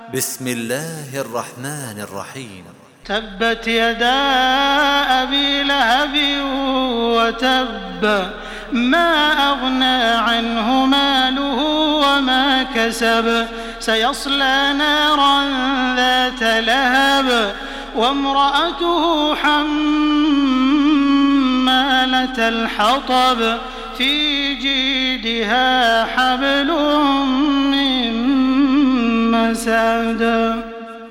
تحميل سورة المسد بصوت تراويح الحرم المكي 1427
مرتل